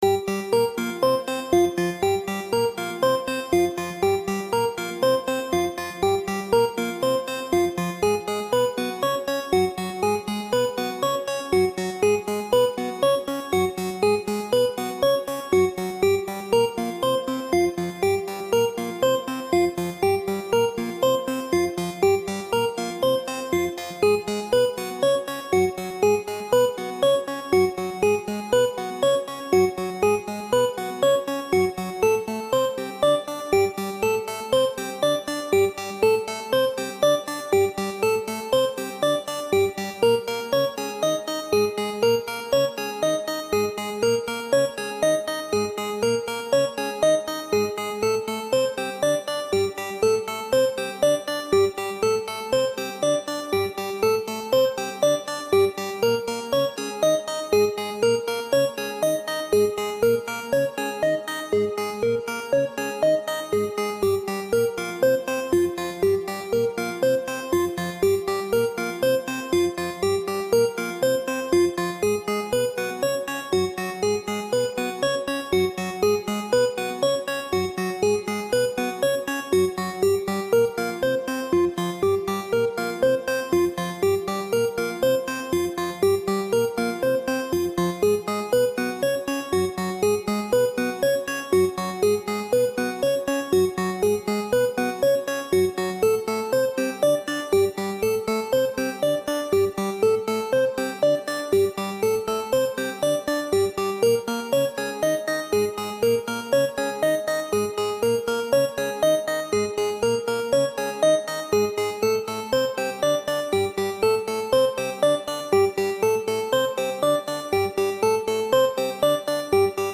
【用途/イメージ】ニュース　メディア　ドキュメント　報道　ナレーション　緊急　事件